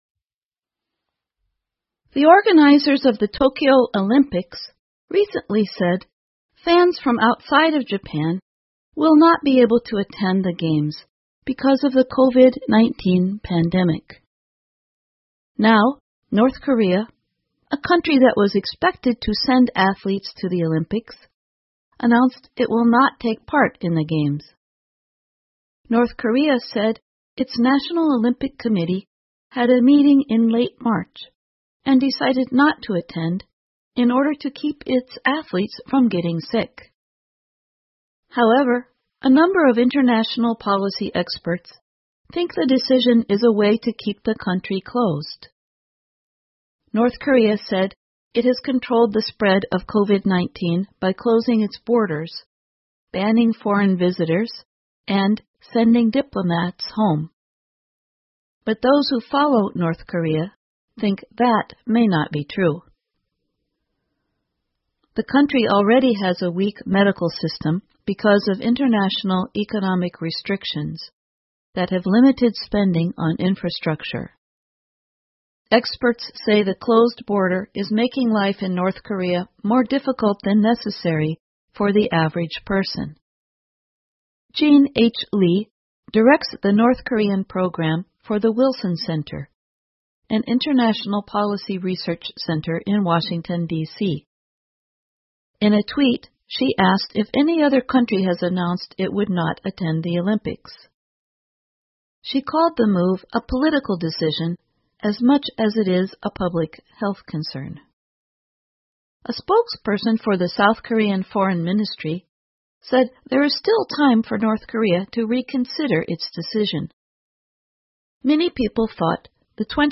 VOA慢速英语2021 朝鲜宣布退出东京奥运会 听力文件下载—在线英语听力室